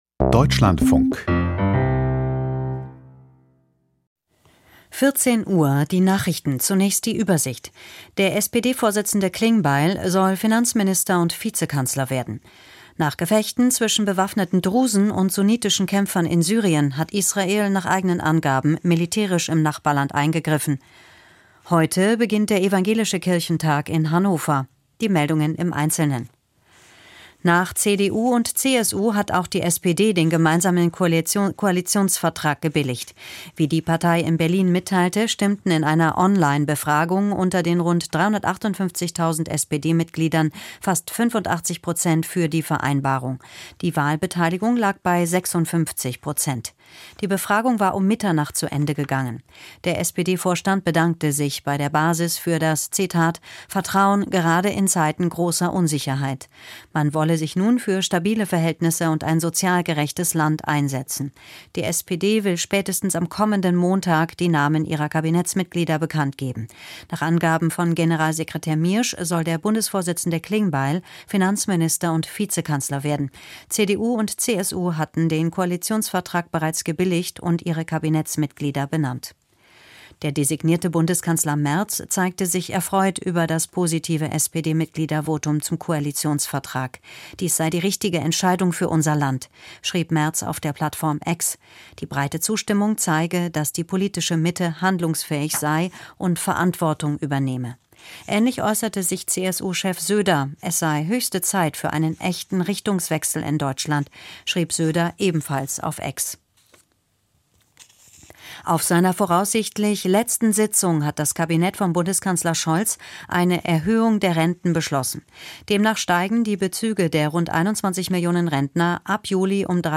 Die Deutschlandfunk-Nachrichten vom 30.04.2025, 14:00 Uhr